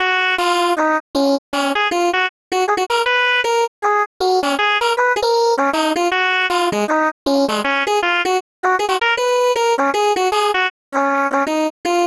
carol riff.wav